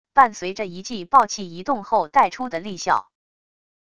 伴随着一记爆气移动后带出的利啸wav音频